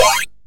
monster_skill.mp3